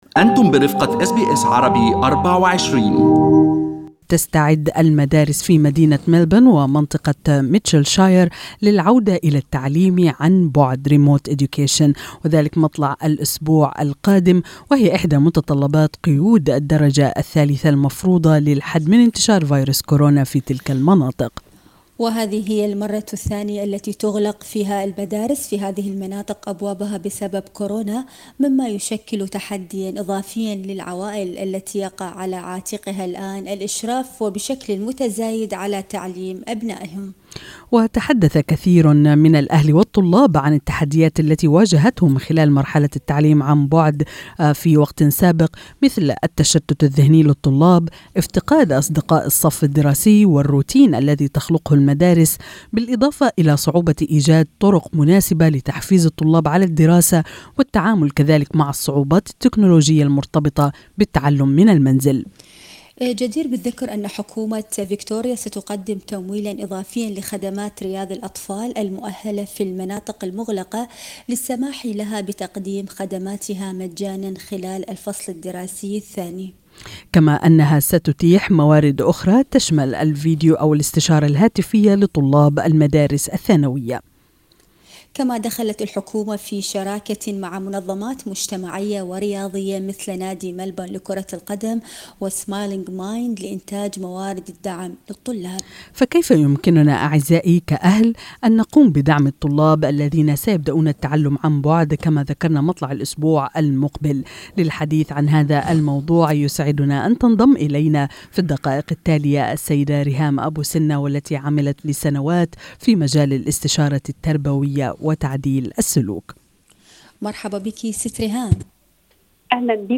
استمعوا الى اللقاء